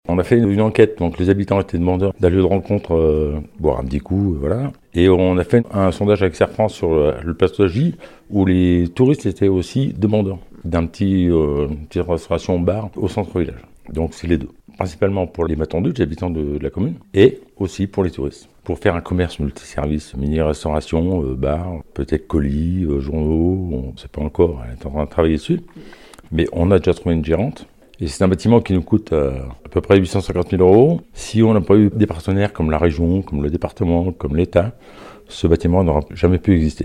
A Saint-Sigismond se déroule un chantier de restructuration et de rénovation énergétique du bâtiment de la Lyre (ancien bâtiment de la musique de Saint-Sigismond qui était à l’époque l’une des premières fanfares de France) en vue d’accueillir un commerce de proximité multiservices (épicerie, dépôt de pains et de colis..) ainsi qu’un appartement comme l’explique le maire Eric MISSILLIER.